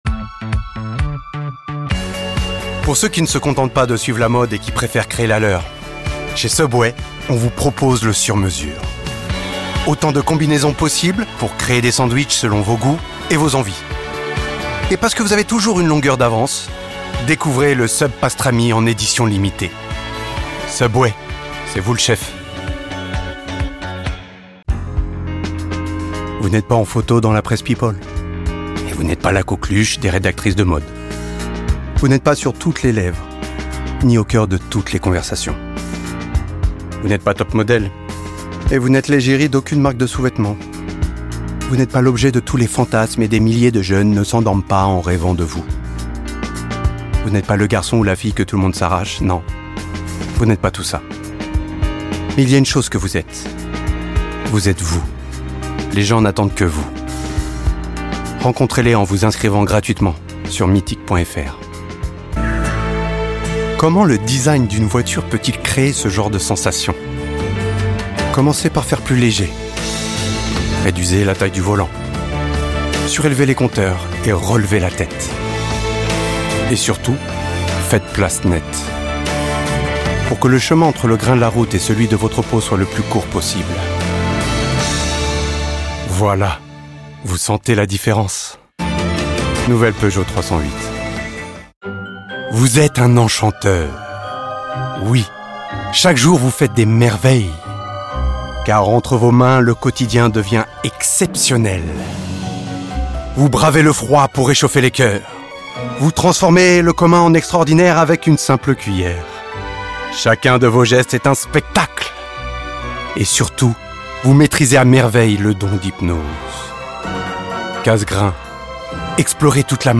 Voix off
35 - 45 ans - Baryton